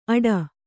♪ aḍa